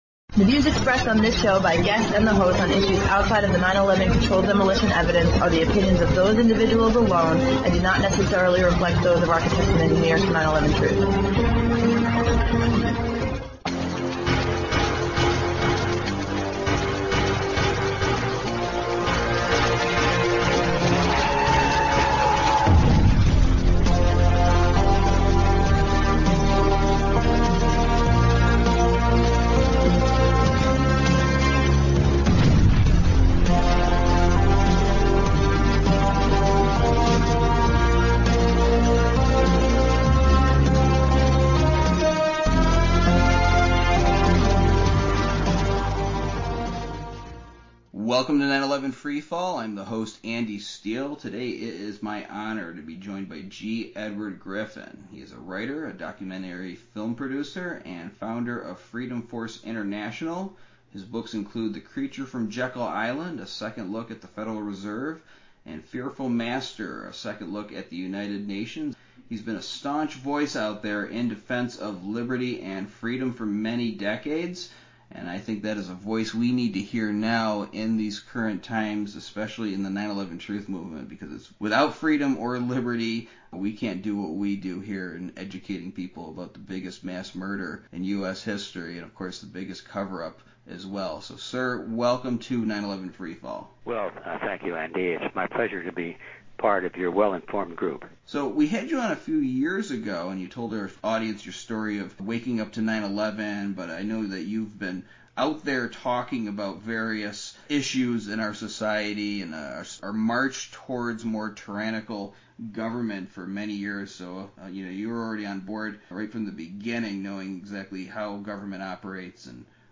Talk Show Episode
Guest, G. Edward Griffin on liberty and truth